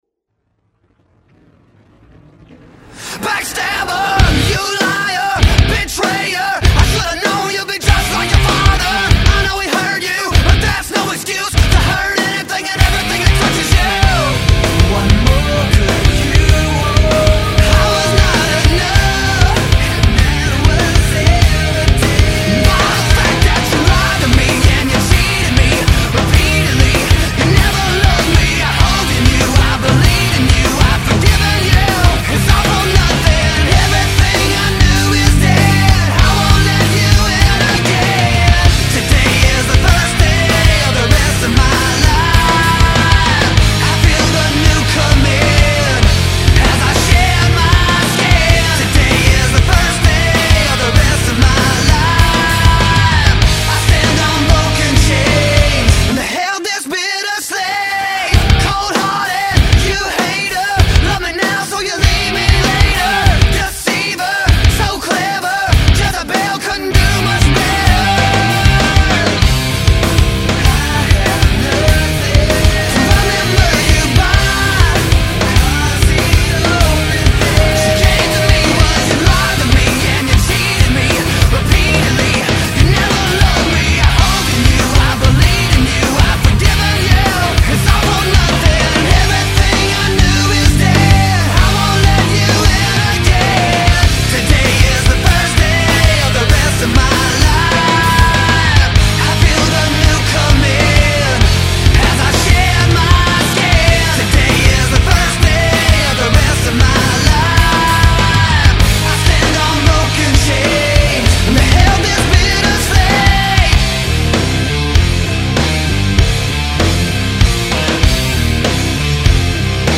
Nu-metal